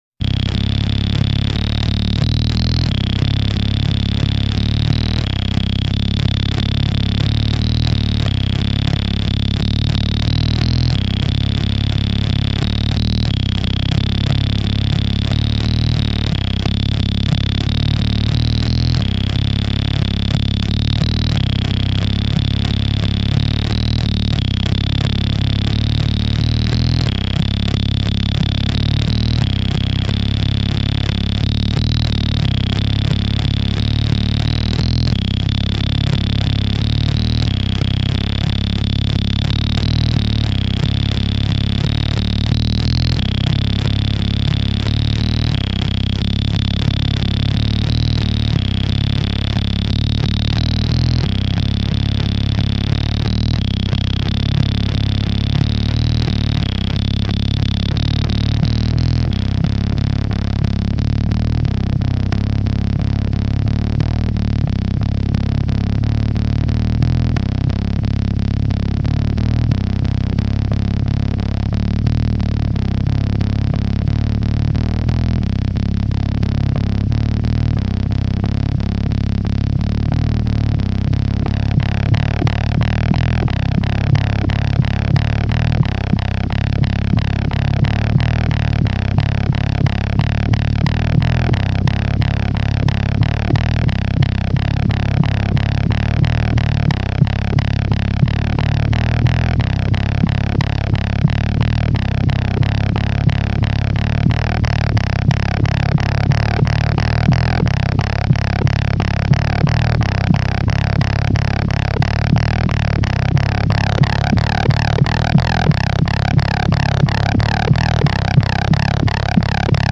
primitive synth explorations
long-form power-electronic paralysis
Simultaneously claustrophobic and cosmic